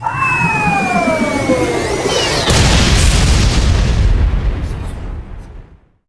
meteor_03.wav